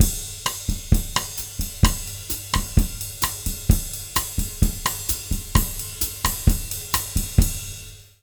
130BOSSA02-L.wav